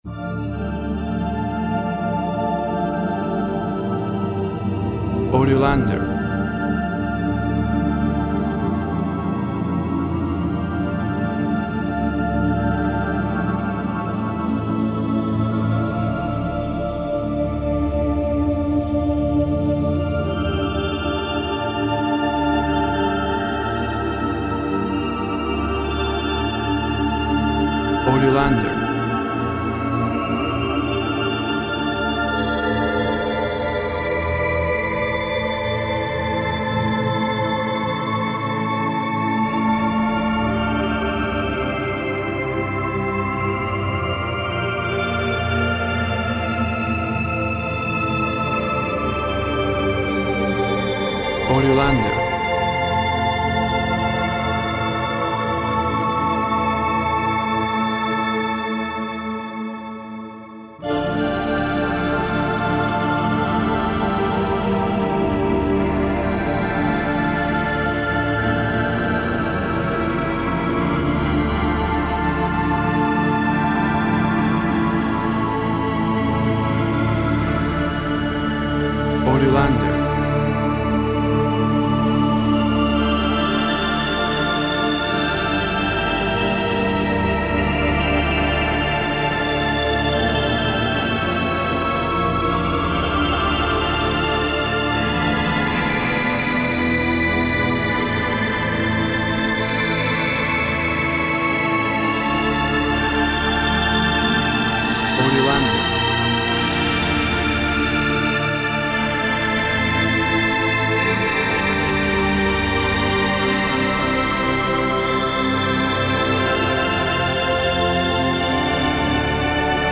Cathedral organ plays a somber waltz in the vastness.
Tempo (BPM) 72